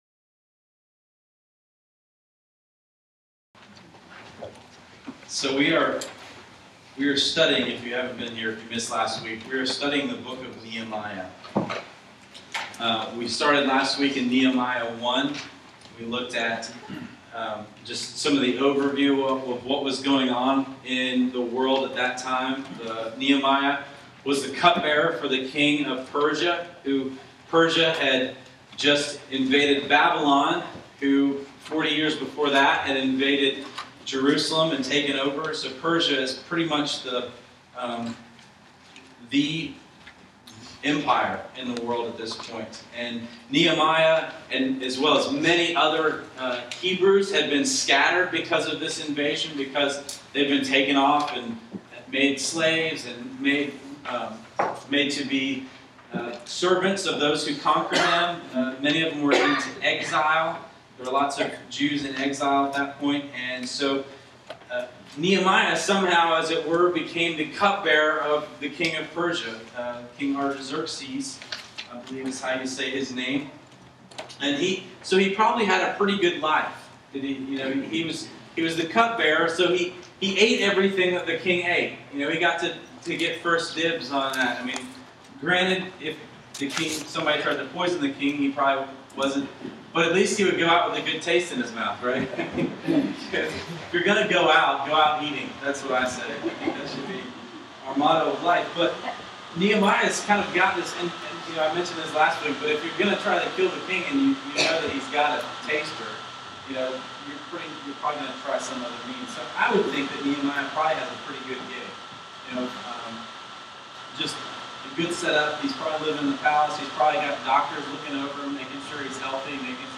Admittedly, my notes this week are bare bones, and the audio is not direct, it is from the mic on my laptop.